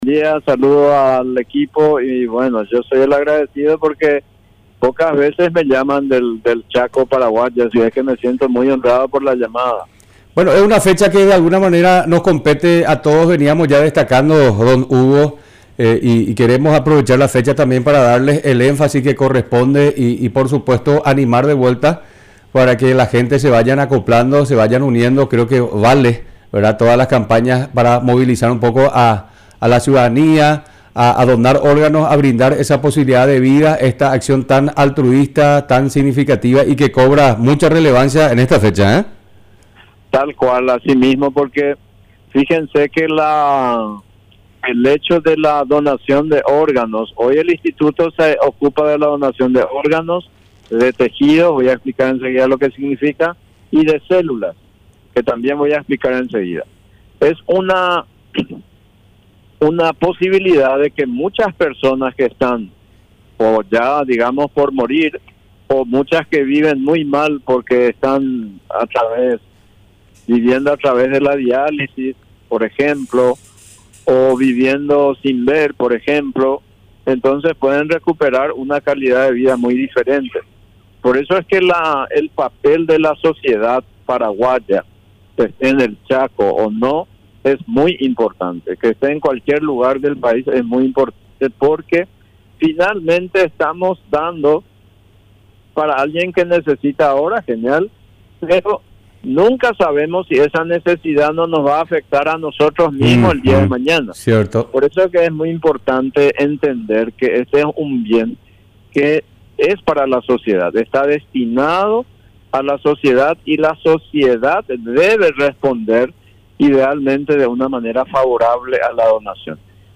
Entrevistas / Matinal 610 Día Nacional del Transplante Jul 09 2024 | 00:18:39 1x 00:00 / 00:18:39 Subscribe Share RSS Feed Share Link Embed